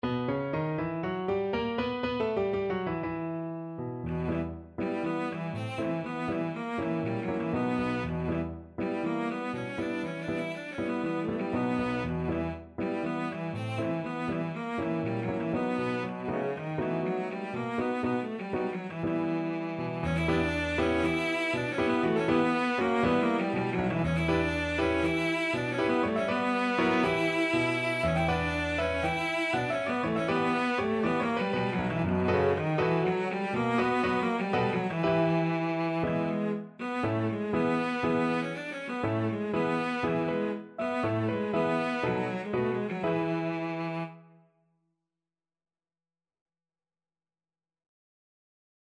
World Trad. Varshaver Freylekhs (Klezmer) Cello version
Cello
2/4 (View more 2/4 Music)
B minor (Sounding Pitch) (View more B minor Music for Cello )
Allegro (View more music marked Allegro)
World (View more World Cello Music)